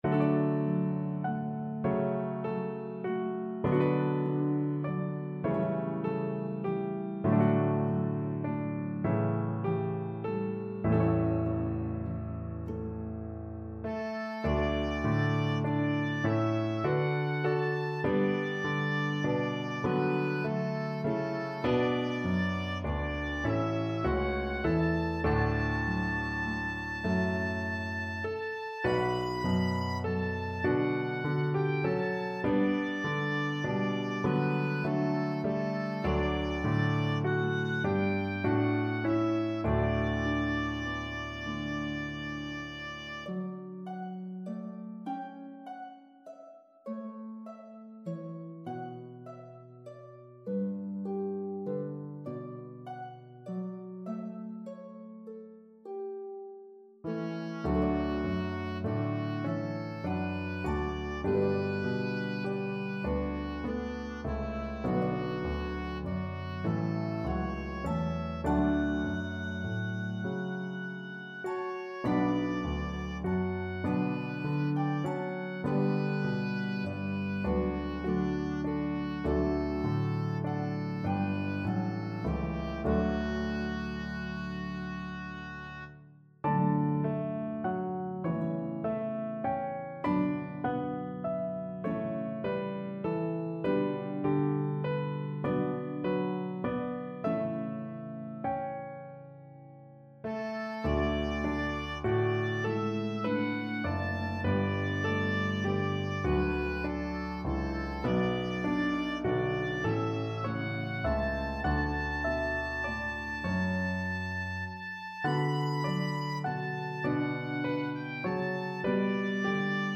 Harp, Piano, and Oboe version